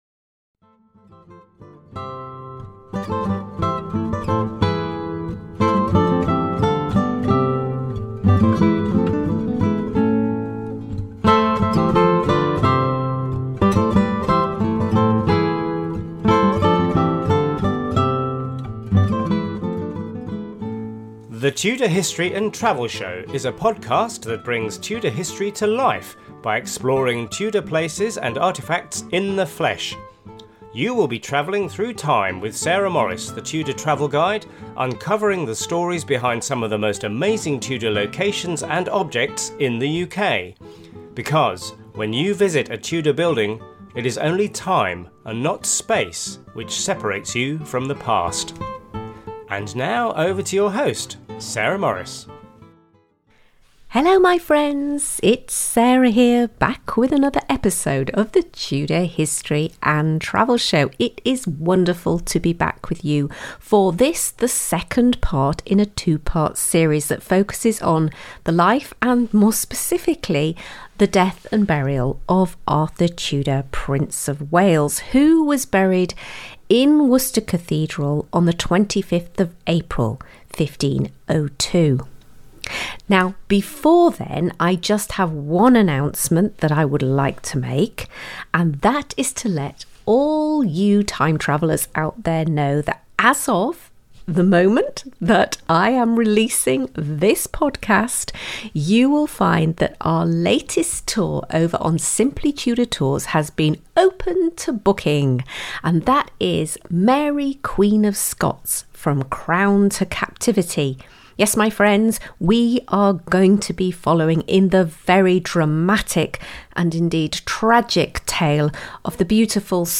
Here is a show notes page accompanying this on-location podcast from Worcester Cathedral.